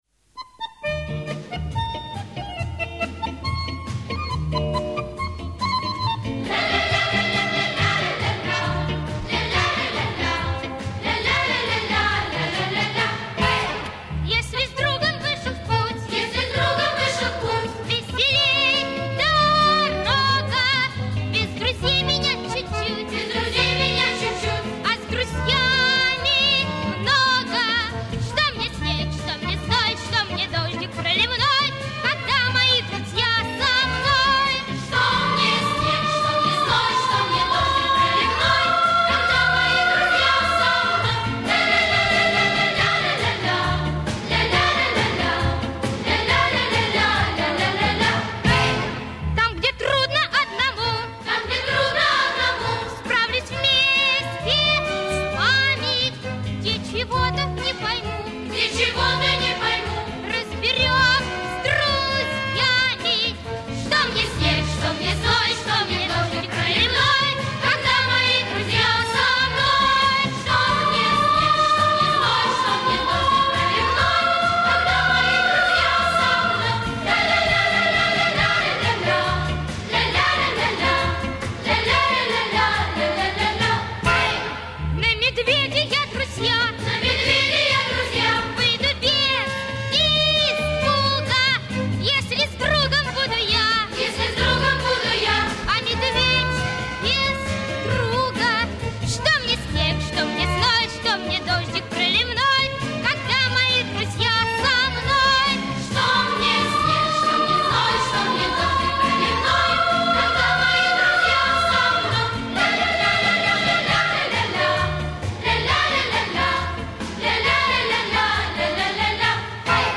Детская песенка про друзей